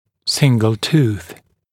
[‘sɪŋgl tuːθ][‘сингл ту:с]одиночный зуб